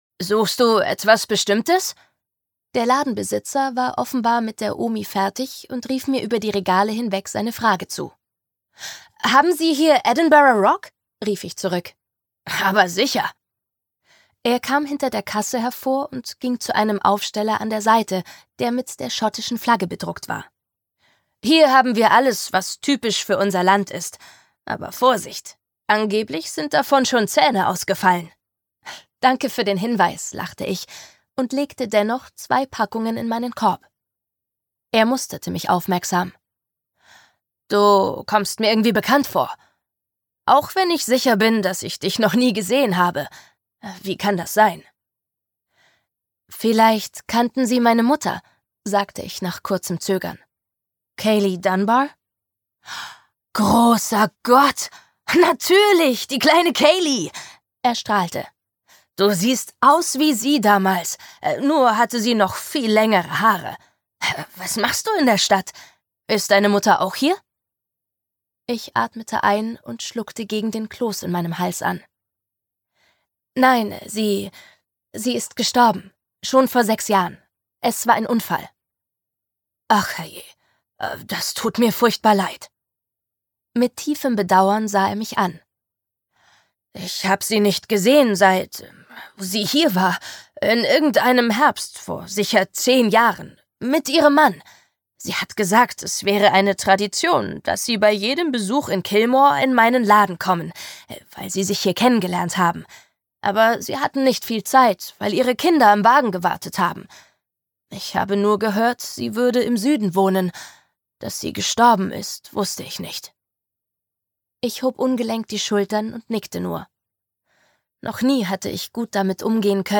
Don't LOVE me (Teil 1) Ungekürzte Lesung